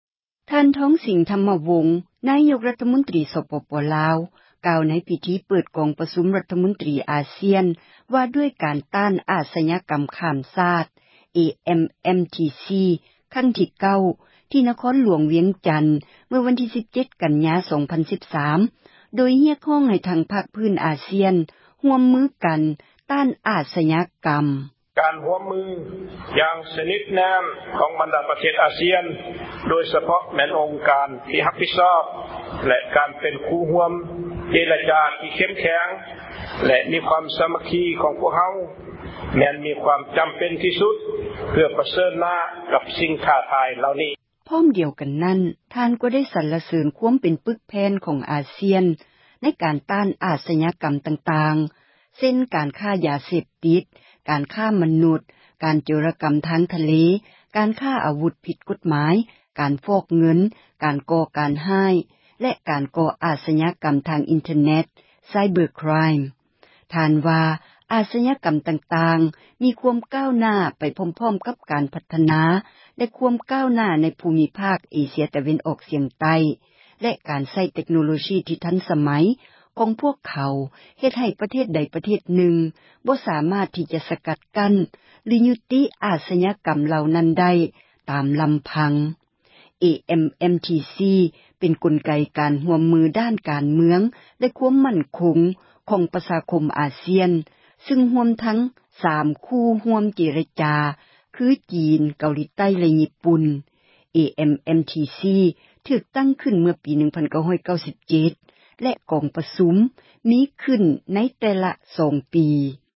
ທ່ານ ທອງສິງ ທັມມະວົງ ນາຍົກຣັຖມົນຕຣີ ສປປລາວ ກ່າວໃນພິທີ ເປີດກອງປະຊຸມຣັຖມົນຕຣີ ອາຊຽນ ວ່າດ້ວຍການ ຕ້ານອາສຍາກັມ ຂ້າມຊາດ AMMTC ຄັ້ງທີ 9 ທີ່ນະຄອນຫລວງວຽງຈັນ ເມື່ອວັນທີ 17 ກັນຍາ 2013 ໂດຍຮຽກຮ້ອງໃຫ້ທັງພາກພື້ນອາຊຽນ ຮ່ວມມືກັນ ຕ້ານອາສຍາກັມ. ທ່ານກ່າວຕອນນຶ່ງວ່າ: